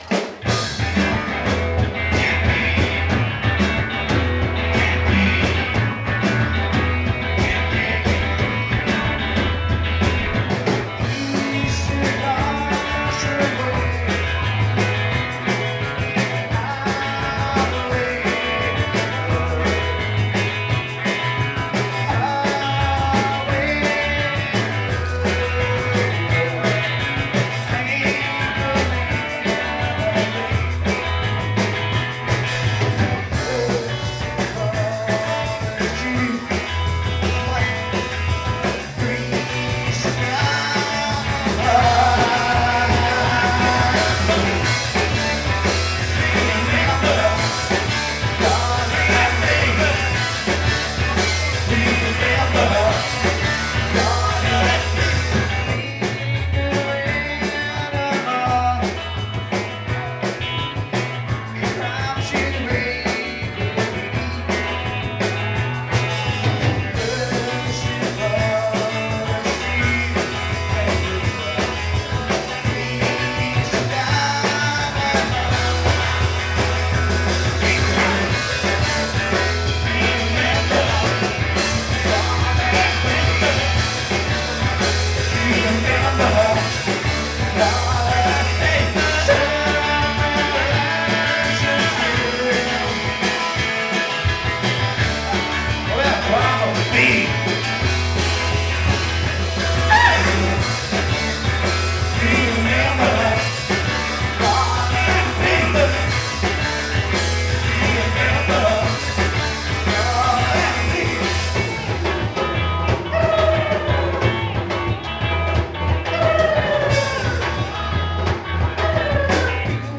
The Strand Cabaret, Marietta, GA